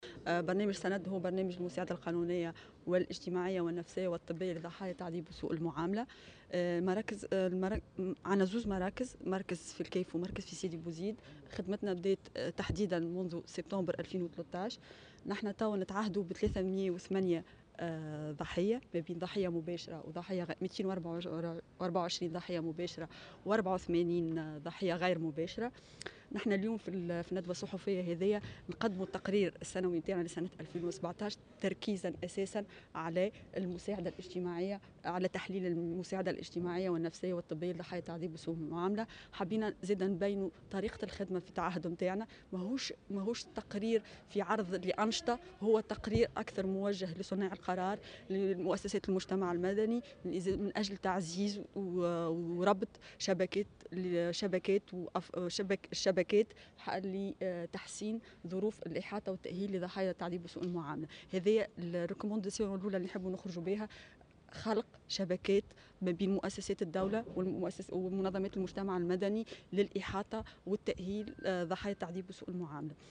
وأضافت في تصريح لمراسلة "الجوهرة أف أم" أن برنامج "سند" يقوم بتقديم المساعدة القانونية و الاجتماعية والطبية والنفسية للضحايا وربط شبكات التواصل بين مؤسسات الدولة ومنظمات المجتمع المدني لتحسين ظروف الإحاطة النفسية.